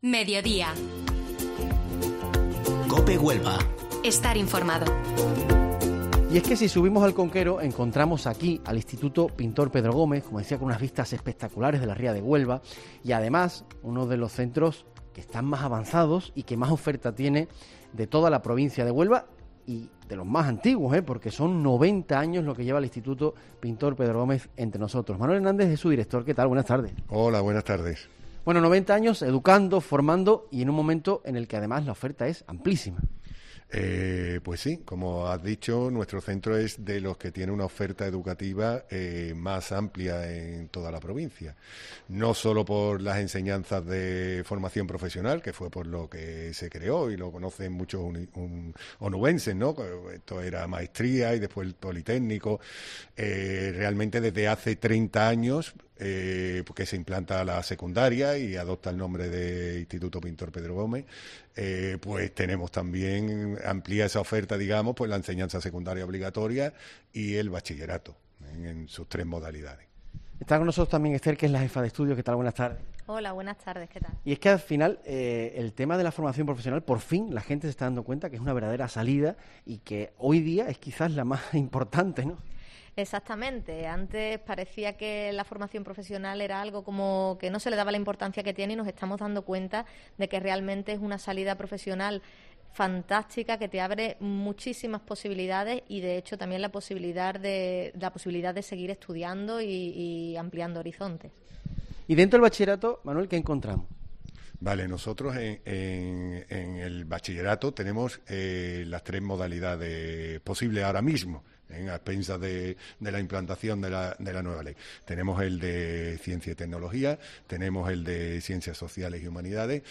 Mediodía COPE Huelva desde el Instituto Pintor Pedro Gómez